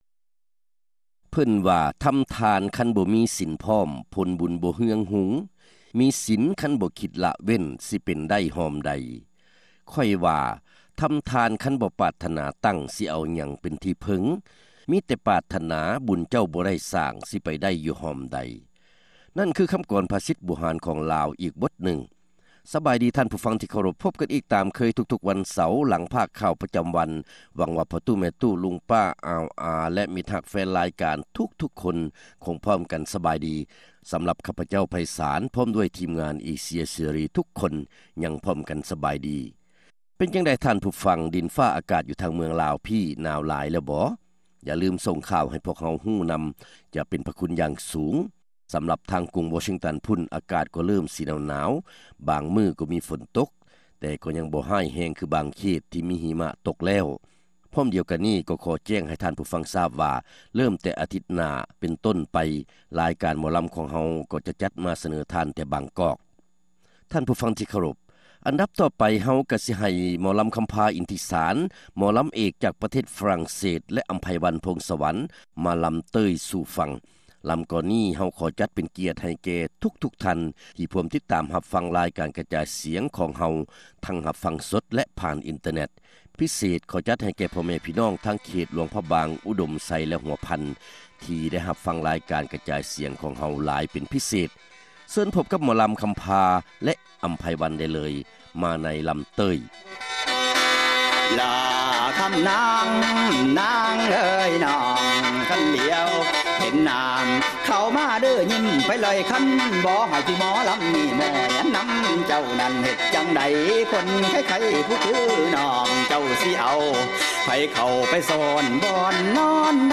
ຣາຍການໜໍລຳ ປະຈຳສັປະດາ ວັນທີ 17 ເດືອນ ພຶສະຈິກາ ປີ 2006